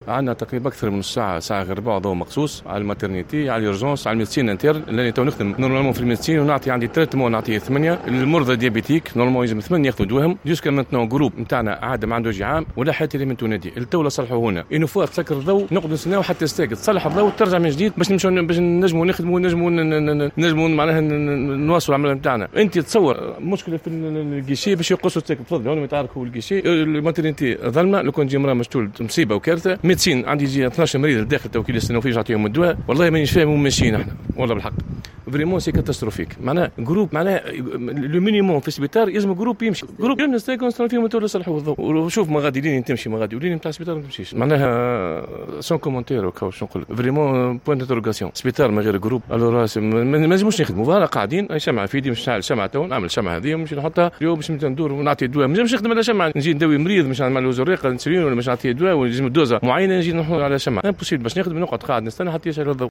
ممرّض